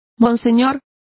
Complete with pronunciation of the translation of monseigneurs.